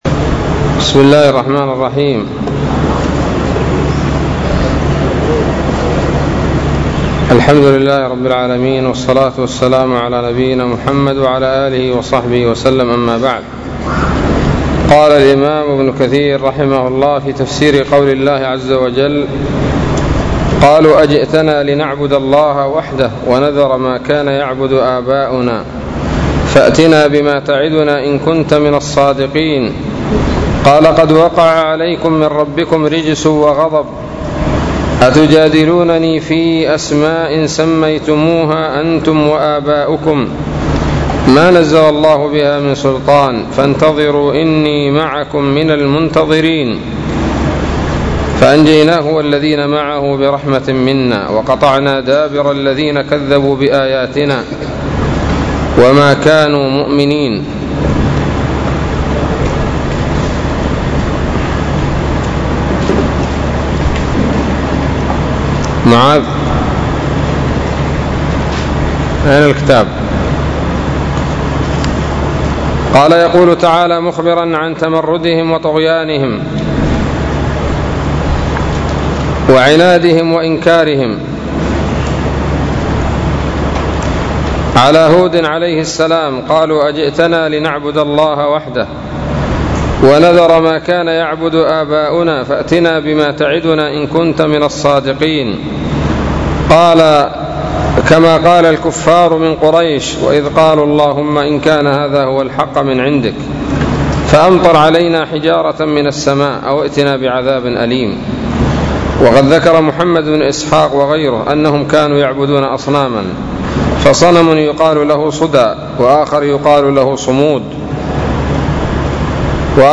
الدرس الثامن والعشرون من سورة الأعراف من تفسير ابن كثير رحمه الله تعالى